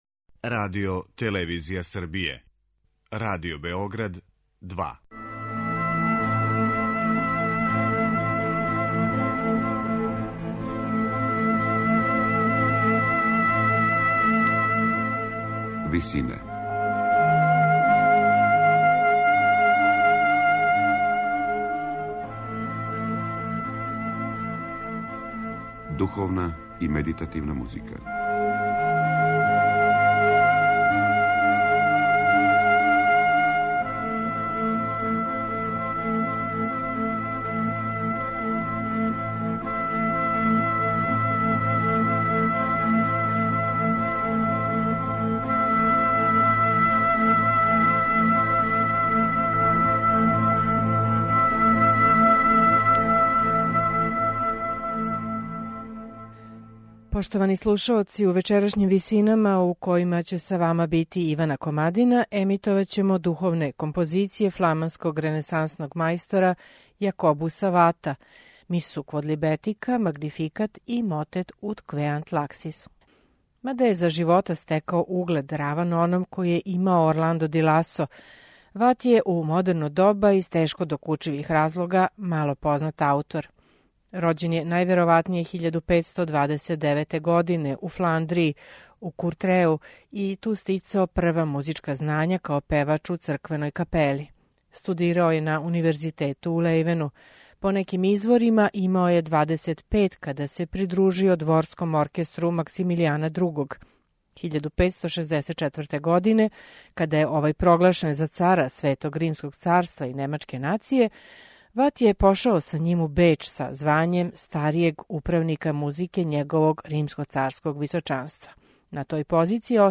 Мада је за живота стекао углед раван оном који је имао Орландо ди Ласо, фламански ренесансни мајстор Јакобус Ват је у модерно доба, из тешко докучивих разлога, мало познат аутор.
Јакобус Ват: Духовне композиције
медитативне и духовне композиције